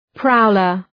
{‘praʋlər}